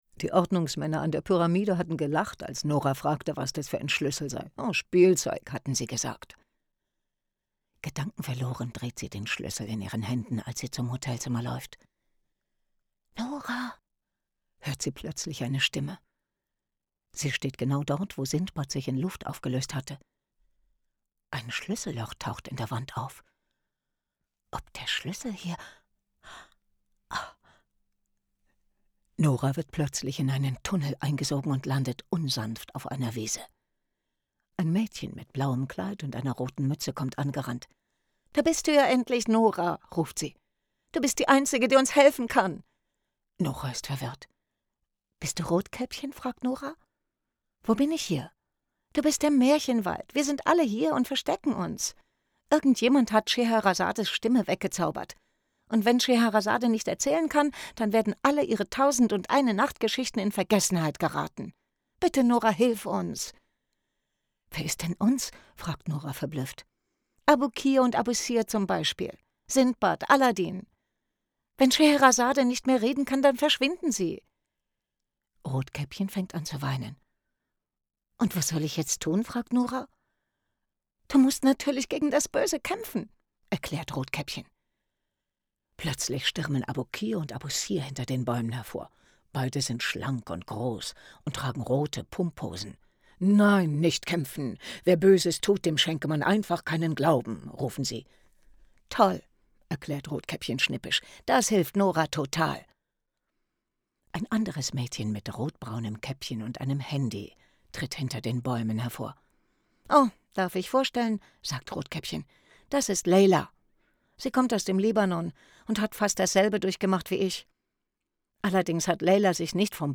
Geschichtenerzähler und Darsteller präsentieren zeitgenössische Geschichten und reflektieren, wie sich ihr Beruf verändert hat.
Storytellers and performers present contemporary tales and reflect on how their profession has changed.